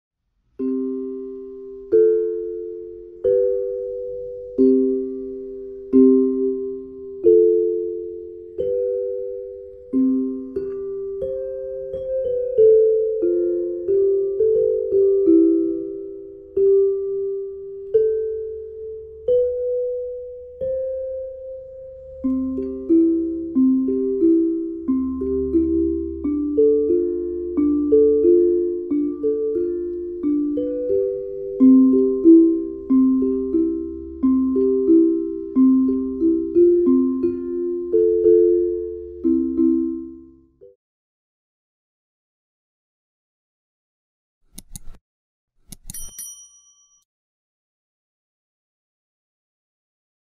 SONIDO_DE_METALOFONO.mp3